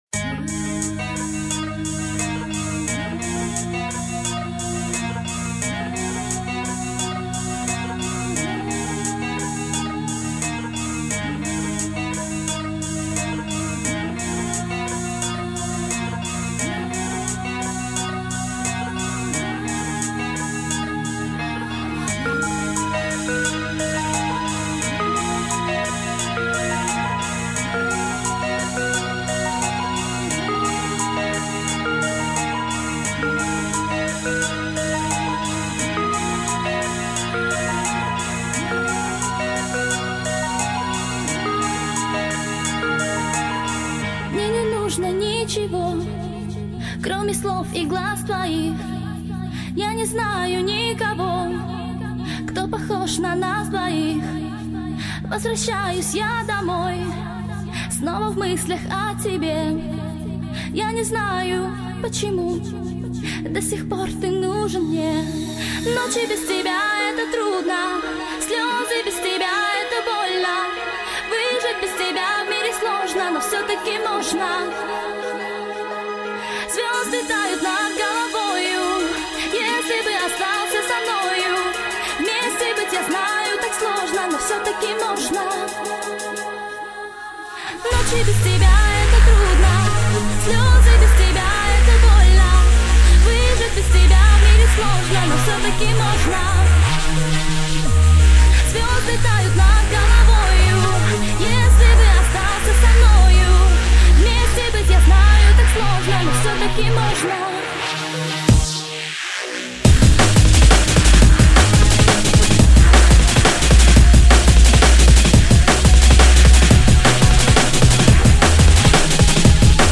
| Категория: Drum and Base | Теги: | Рейтинг: 5.0 |